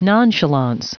Prononciation du mot nonchalance en anglais (fichier audio)
Prononciation du mot : nonchalance